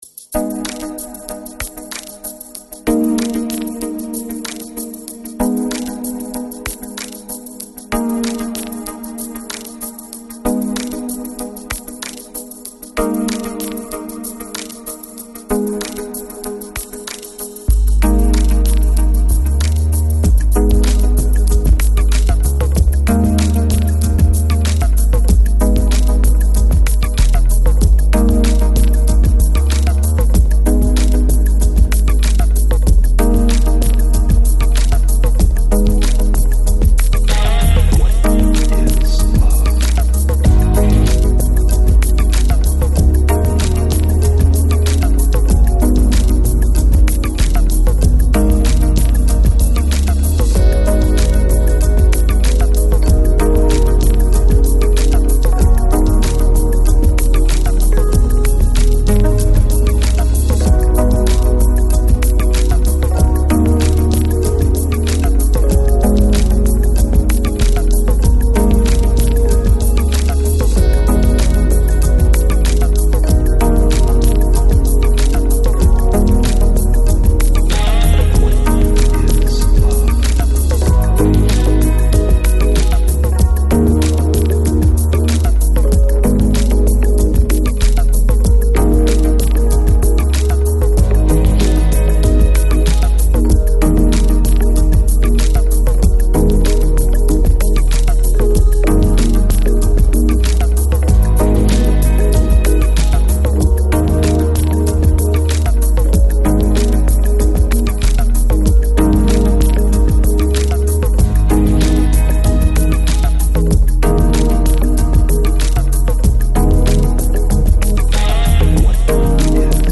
Жанр: Lounge, Lo-Fi, Ambient, New Age, Chill Out